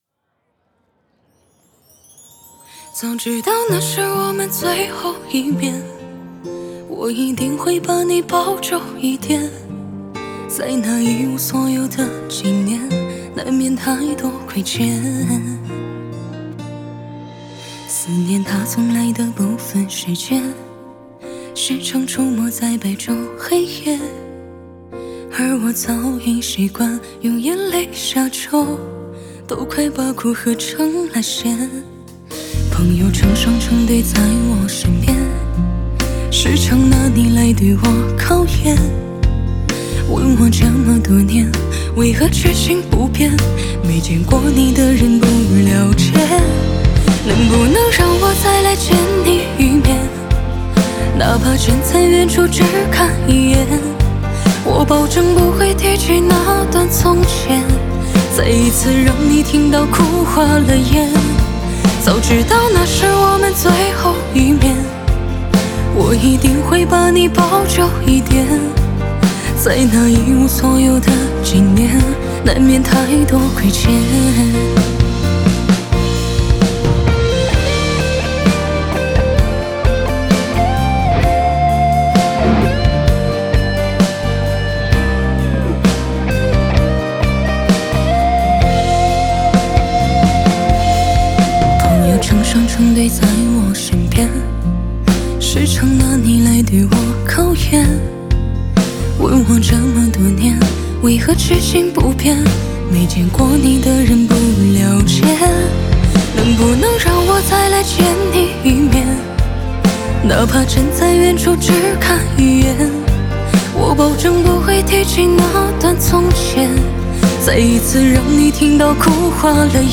吉他